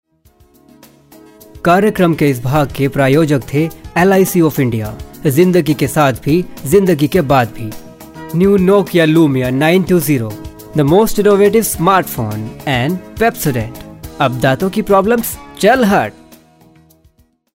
a professional voice over artist with good command over hindi , english and punjabi
Sprechprobe: Werbung (Muttersprache):